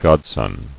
[god·son]